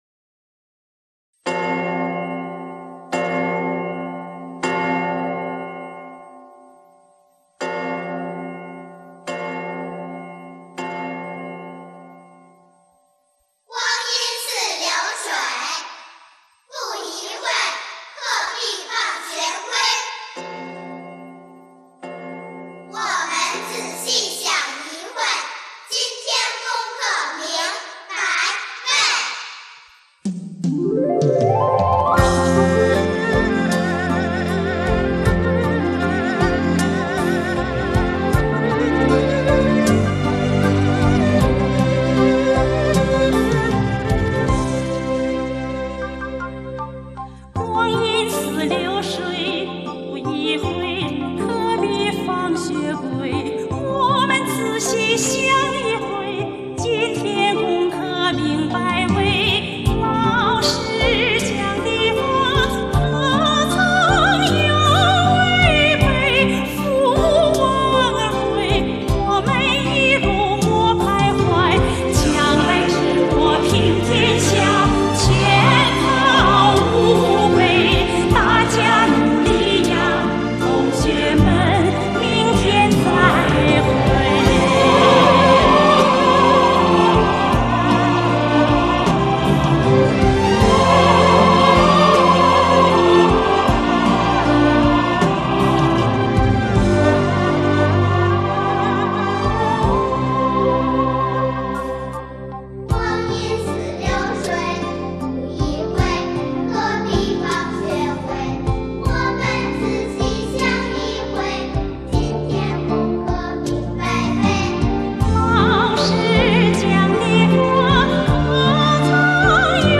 他创作的这首歌内容浅显而立意很高，歌的曲调采用当时全国流行和脍炙人口的民间乐曲《老八板》，旋律深沉而宽广，一字一板，浑厚自然，不仅给人以艺术陶冶，更重要的是具有极强的教育感化功能。